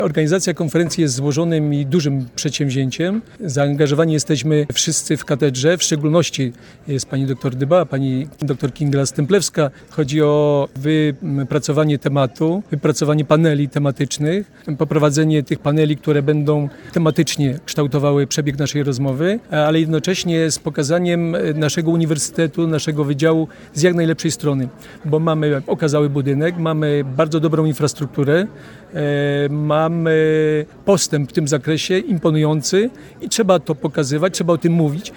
Konferencja-Dylematy-polityki-spoleczno-gospodarczej-1.mp3